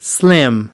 8 slim (adj) /slɪm/ Thon thả, gầy